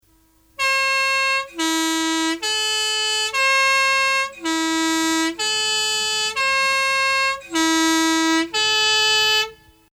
Dreiklanghorn (tütato) (MP3)
Reisepost_11_tuetato.mp3